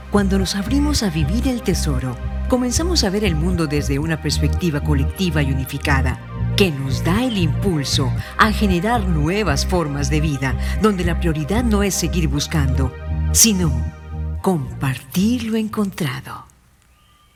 • 8Spanish Female No.5
Brand Ads